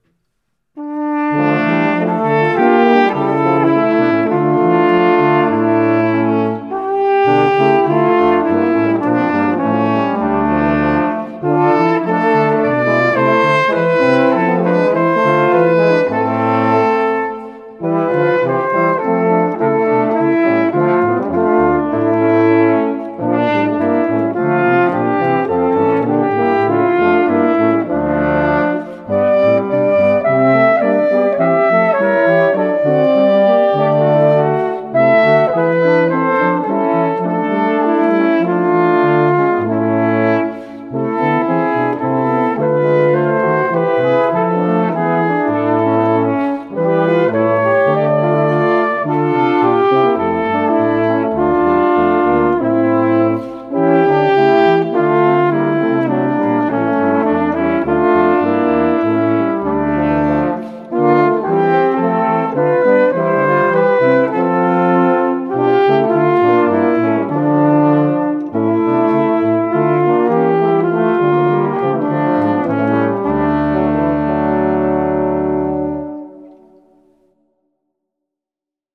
Barocke Kirchenmusik für Blechblasinstrumente
2x Flügelhorn, F-B-Horn, Es-Tuba [1:18]